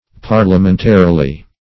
Parliamentarily \Par`lia*men"ta*ri*ly\, adv. In a parliamentary manner.